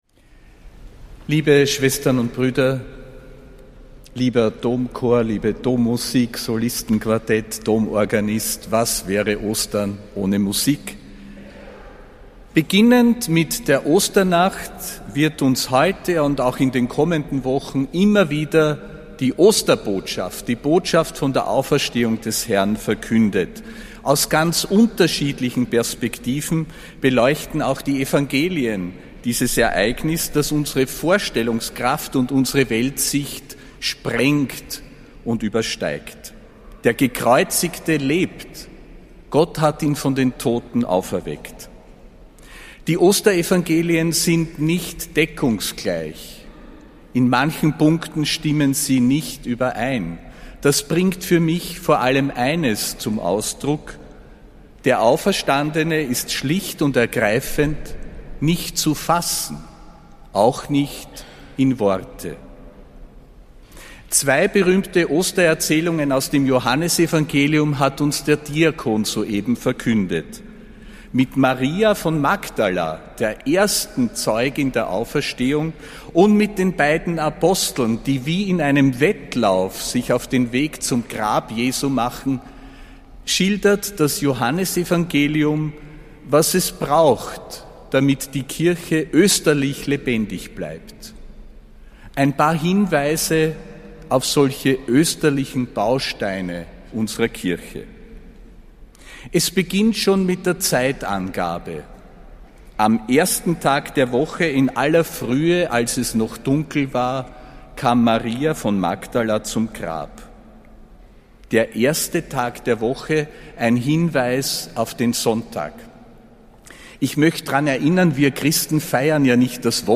Predigt des Apostolischen Administrators Josef Grünwidl zum Ostersonntag, am 20. April 2025.